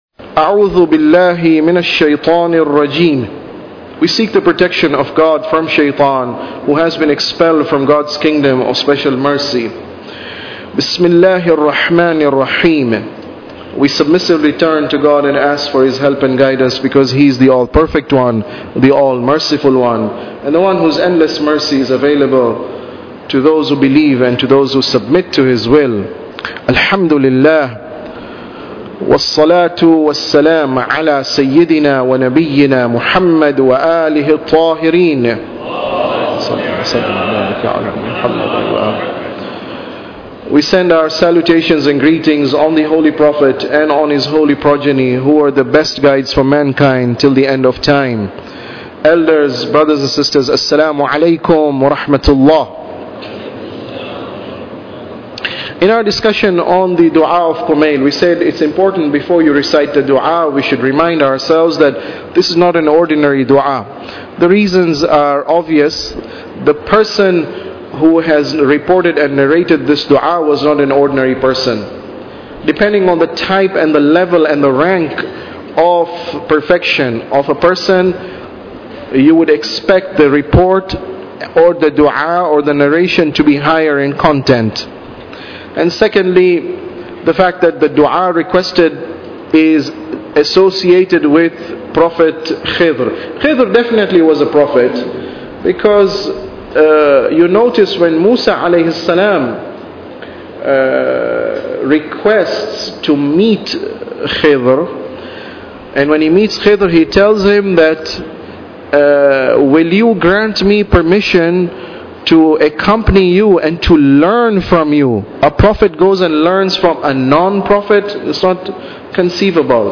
Tafsir Dua Kumail Lecture 9